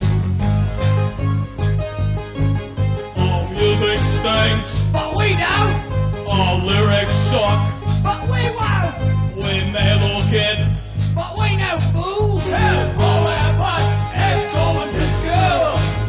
this song combines elements of metal, new wave and punk.
beer bottle percussion
keyboards and beatbox
spackity spack bass